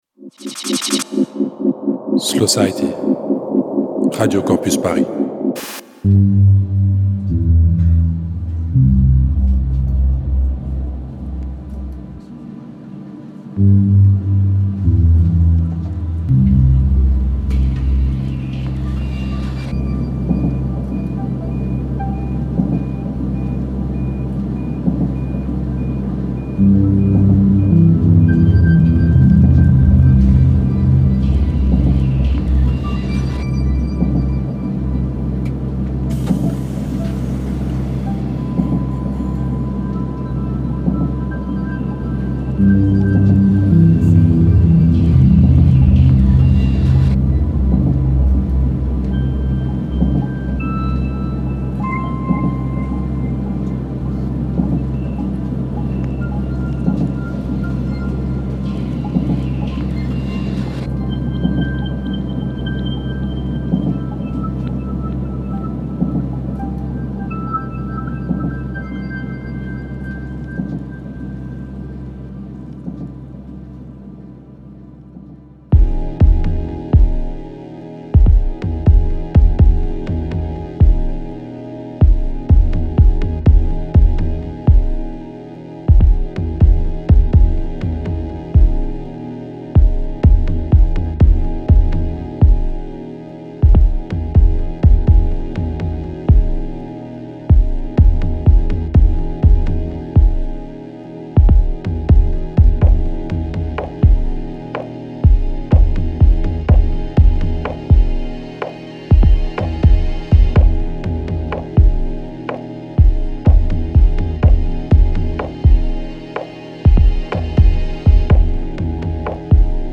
eclectic, wide range, mixed Techno and House -DJ Sets